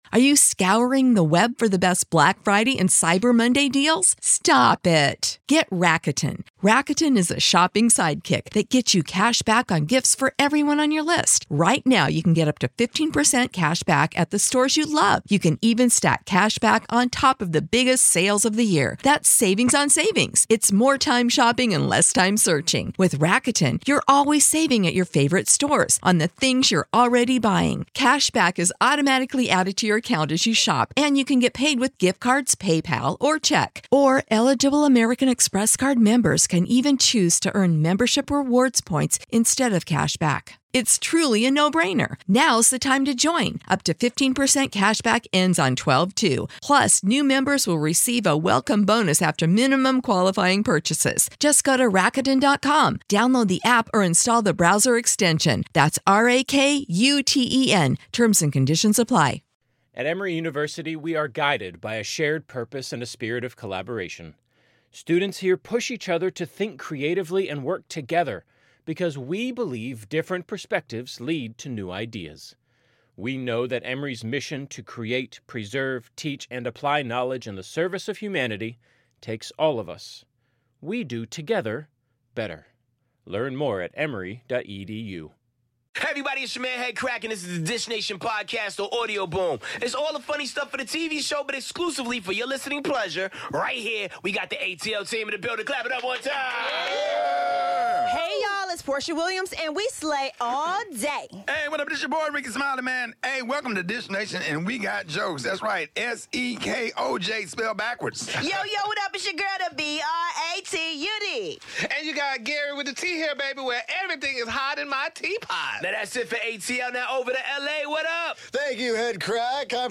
Chris Brown has a yard sale, Blac Chyna's mom Tokyo Toni is in studio dishin' on 'Finding Love ASAP,' and 70-year-old Richard Gere, 'you are the father!' We've got Tokyoxvanity in studio with us so tune in!